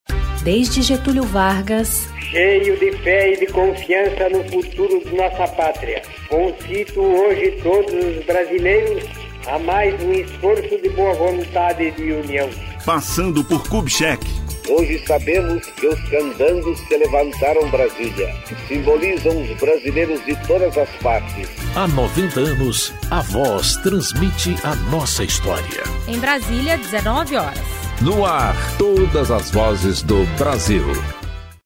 Spots e Jingles produzidos pela rede gov e por órgãos do governo federal.